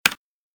keyboardtyping.ogg